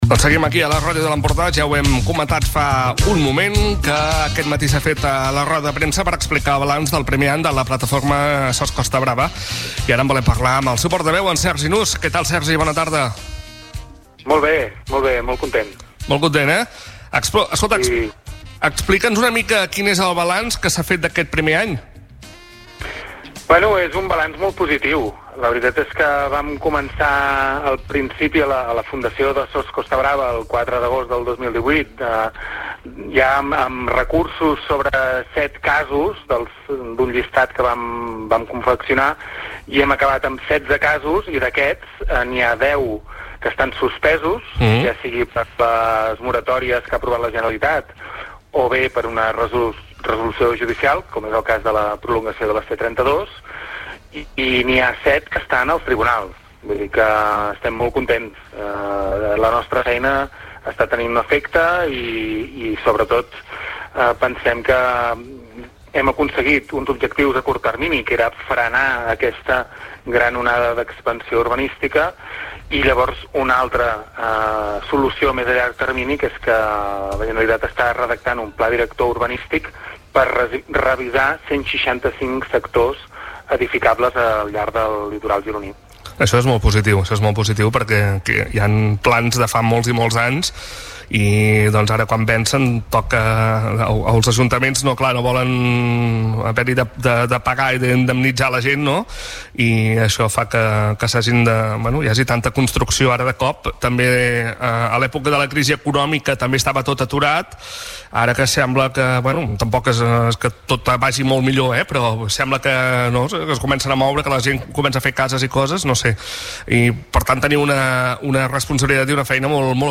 Ràdio Capital. La ràdio de l'Empordà